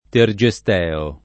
vai all'elenco alfabetico delle voci ingrandisci il carattere 100% rimpicciolisci il carattere stampa invia tramite posta elettronica codividi su Facebook Tergesteo [ ter J e S t $ o ] n. pr. m. — edificio a Trieste (sec. XIX)